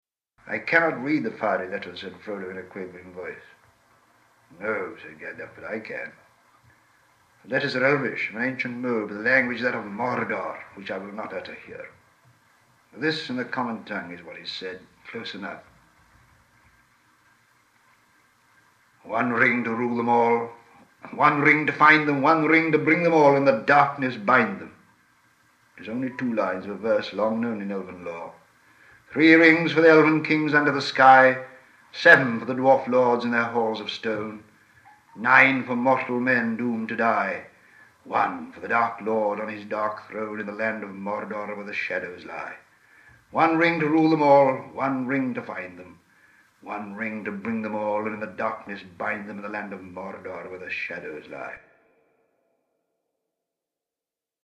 here’s an MP3 of Professor Tolkien himself, recorded in 1952, reading an early and chilling part from the first book. (I tastefully added a bit of room echo to give it some presence.)